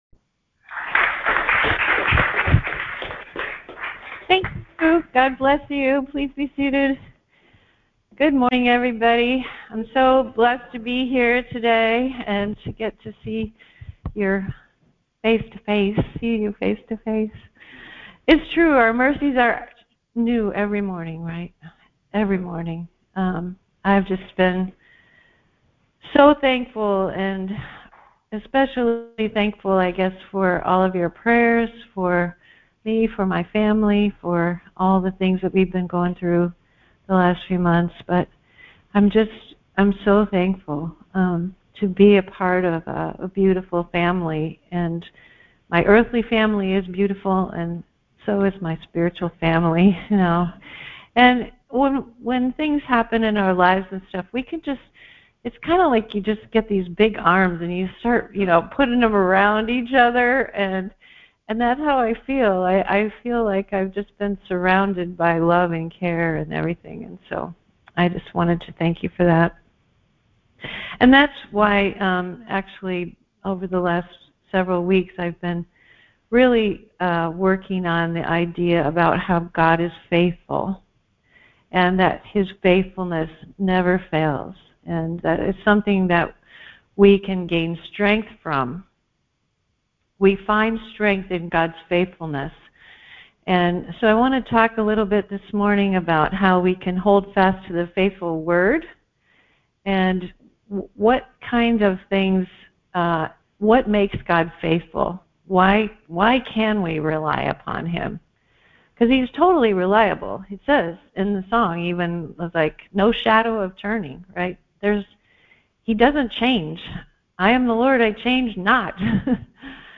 Church in San Diego California